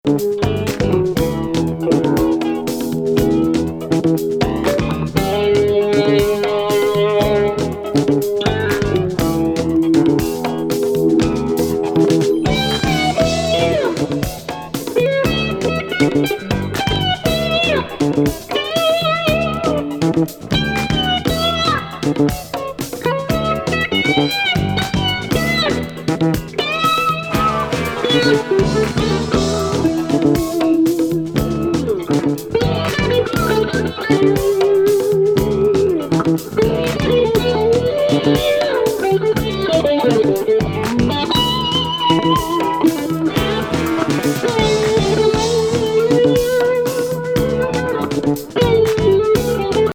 タイト・ディスコ